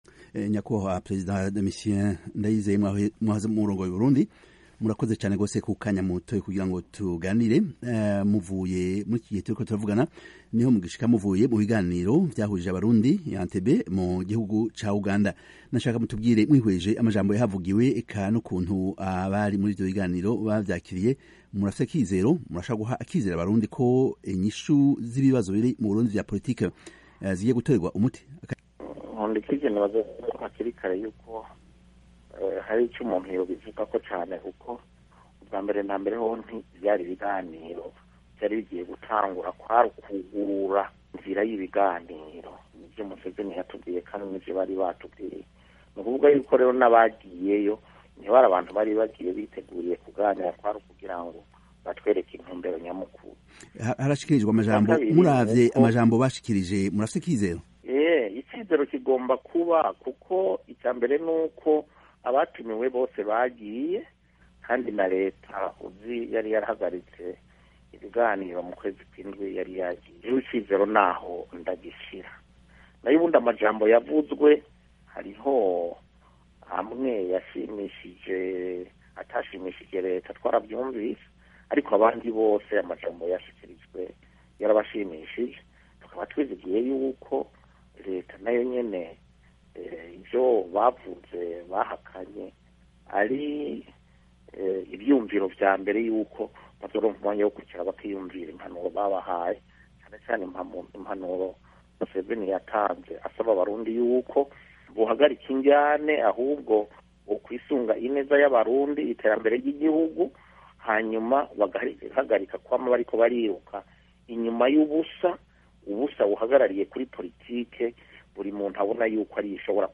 Yatangaje ayo majambo mu kiganiro yahaye...